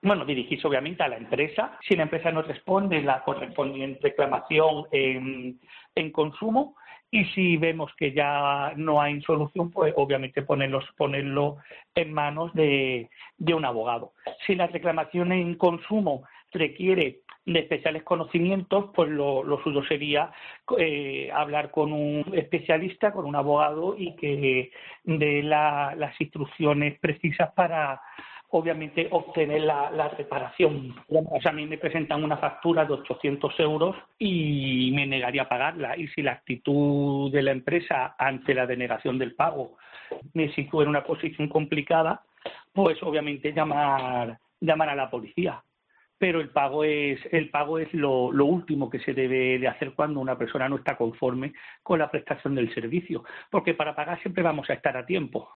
El abogado